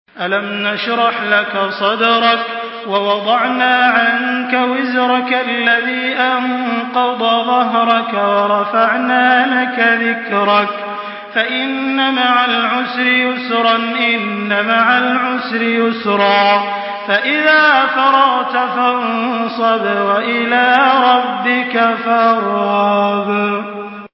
سورة الشرح MP3 بصوت تراويح الحرم المكي 1424 برواية حفص
مرتل حفص عن عاصم